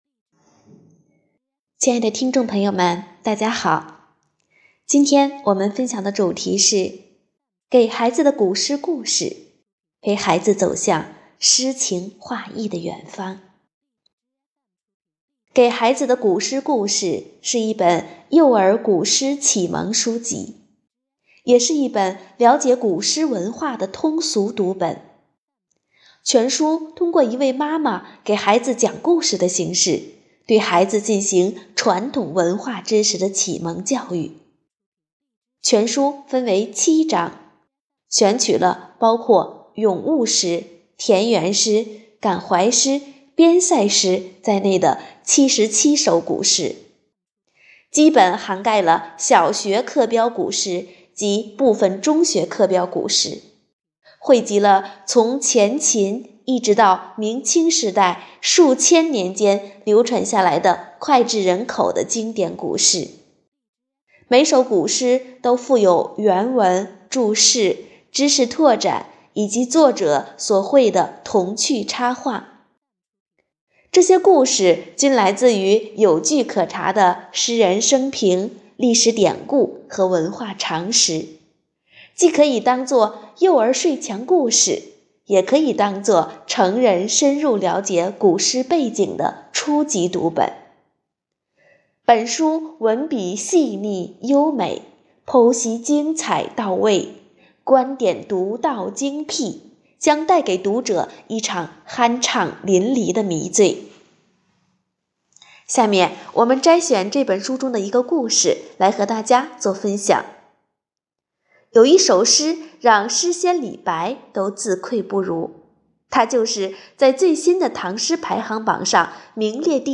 【讲座】丰图讲座 | 《给孩子的古诗故事》陪孩子走向诗情画意的远方
活动地点：丰南图书馆 线上活动